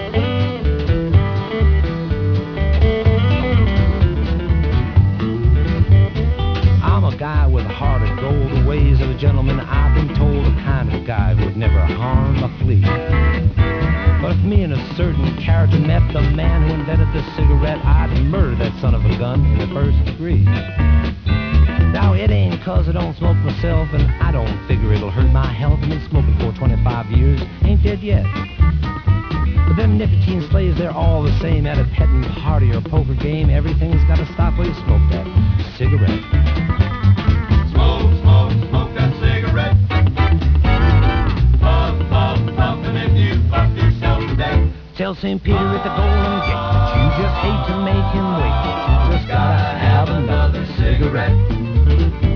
Country song - I'm no fan of countr music, but this song sings the praises of cigarettes.